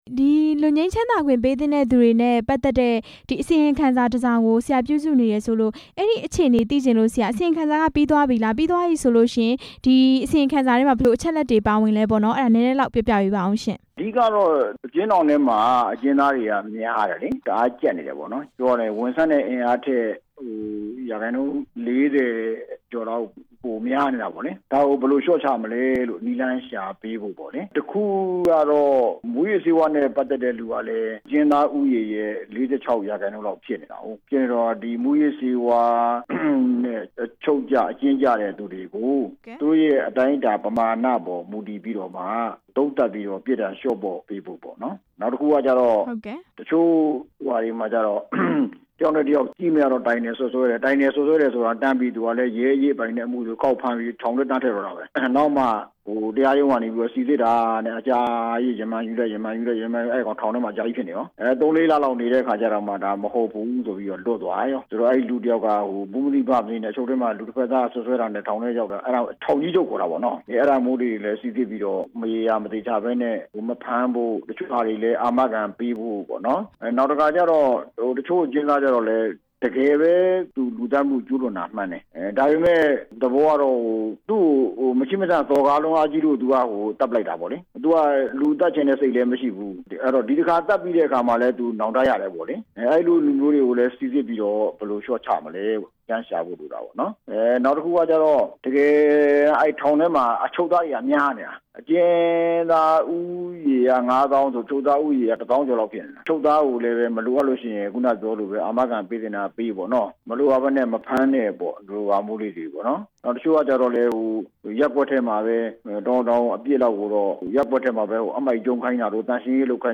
လွတ်ငြိမ်းချမ်းသာခွင့် ပေးသင့်သူတွေနဲ့ ပတ်သက်တဲ့ အစီရင်ခံစာအကြောင်း မေးမြန်းချက်